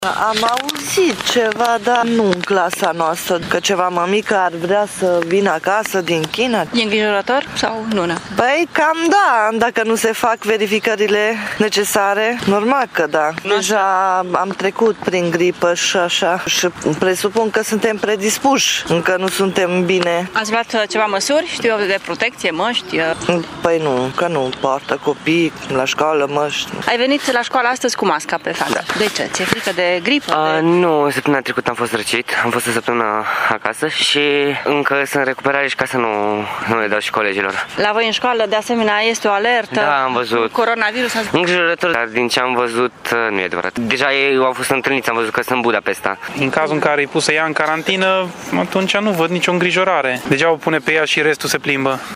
Părinții spun însă că e inutilă măsura de izolare doar pentru mamă. Totuși, măsuri suplimentare de protecție sunt necesare în această perioadă și din cauza ”virușilor locali”: